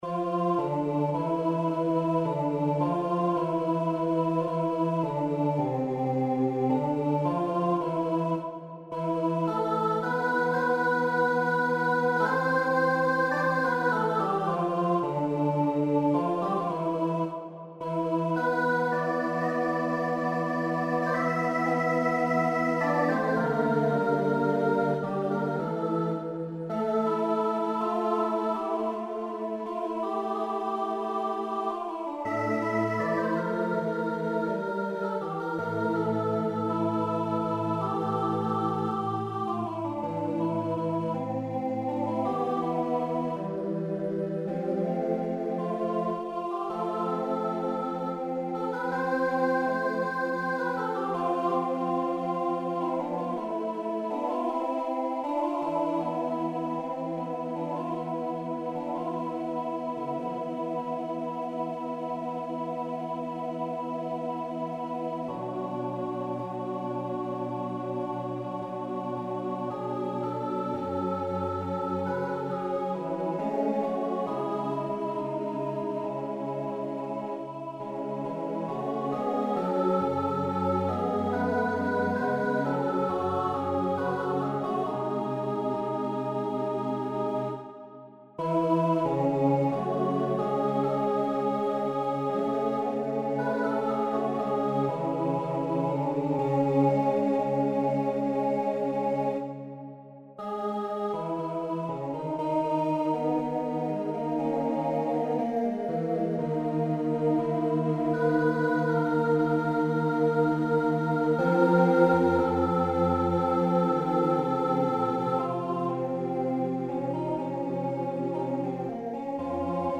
Forces: SATB